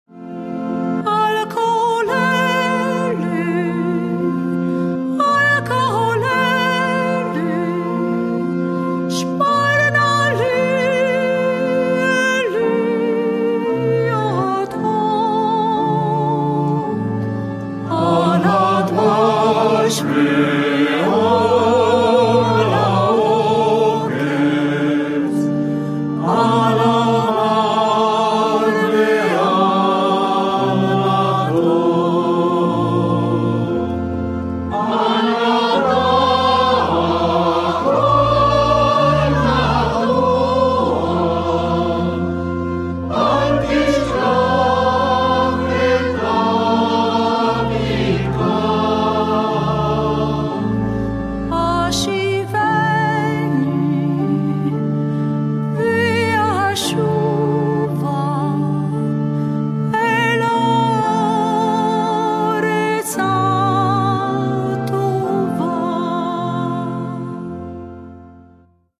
Coro e solista